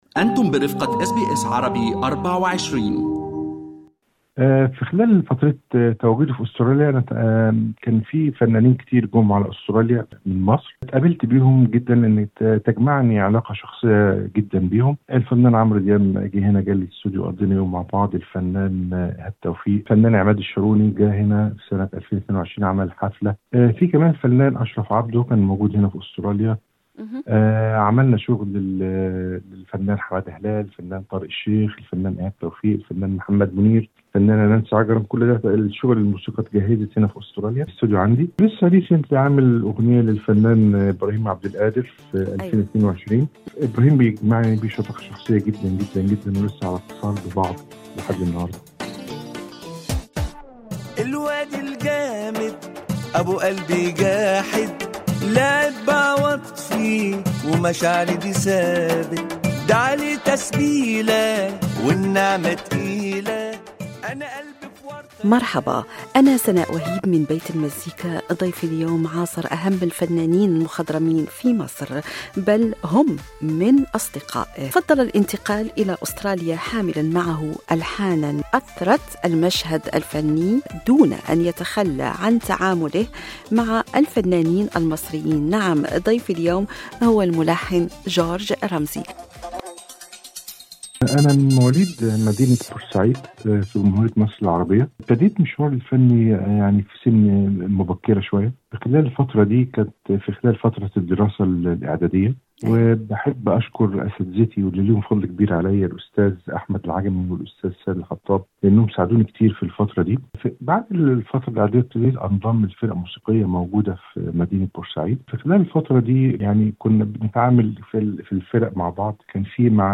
في لقاء مع برنامج بيت المزيكا